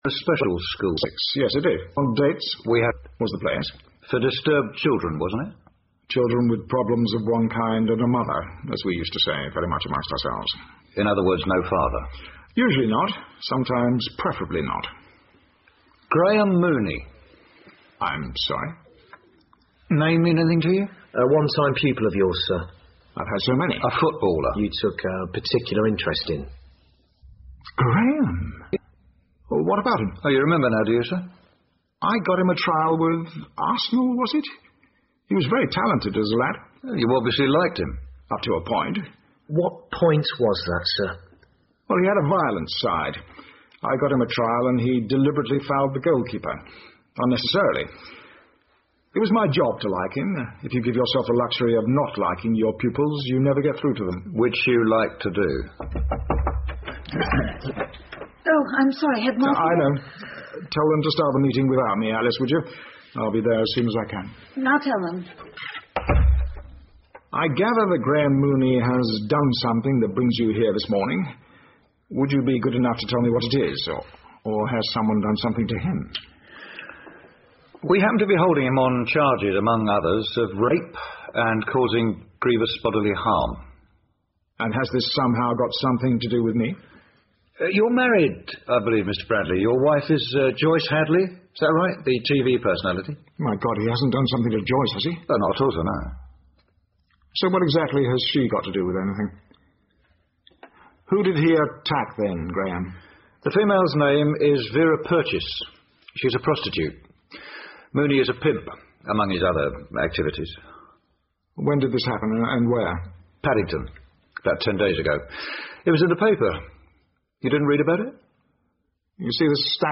英文广播剧在线听 Fame and Fortune - 16 听力文件下载—在线英语听力室